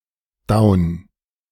Daun (German pronunciation: [daʊn]